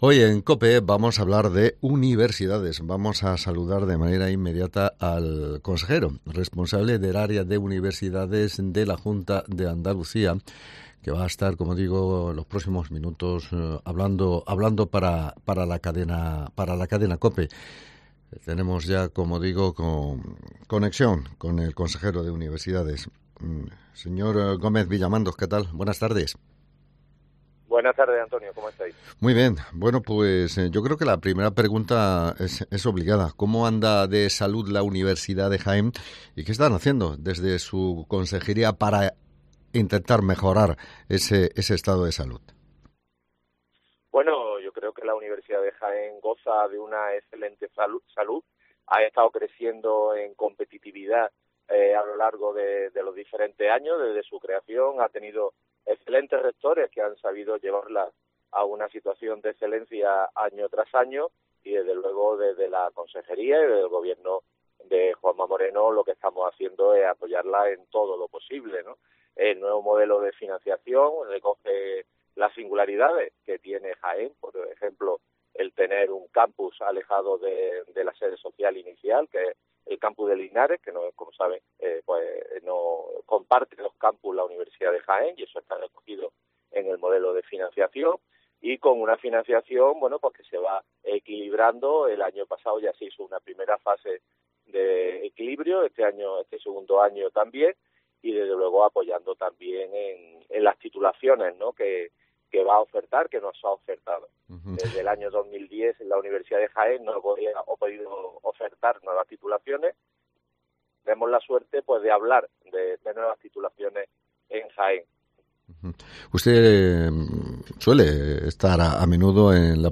Entrevista con el Consejero de Universidades